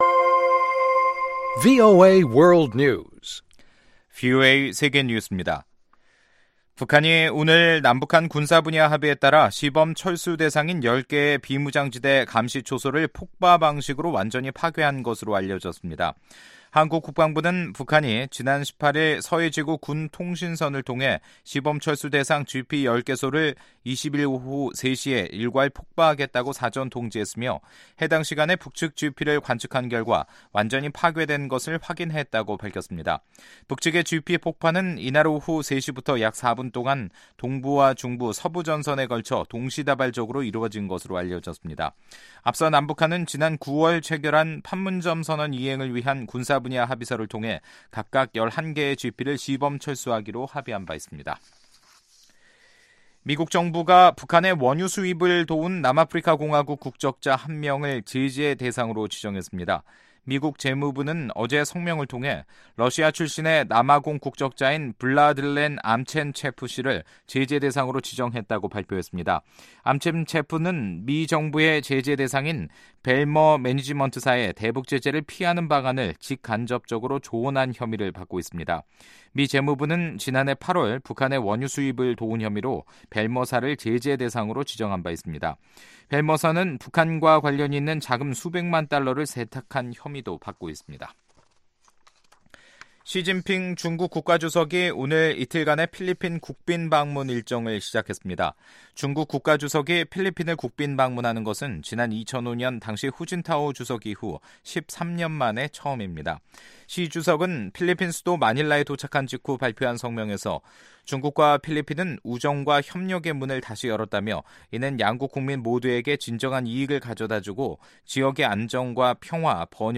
VOA 한국어 간판 뉴스 프로그램 '뉴스 투데이', 2018년 11월 20일 2부 방송입니다. 미국 재무부는 북한을 위한 유류 공급과 돈세탁에 연루된 싱가포르 회사 관계자를 제재 명단에 추가했습니다. 유엔 여성기구는 북한의 여성 폭력을 심각하게 보고 있으며 가해자들이 처벌 받을 수 있도록 준비해야 한다고 밝혔습니다.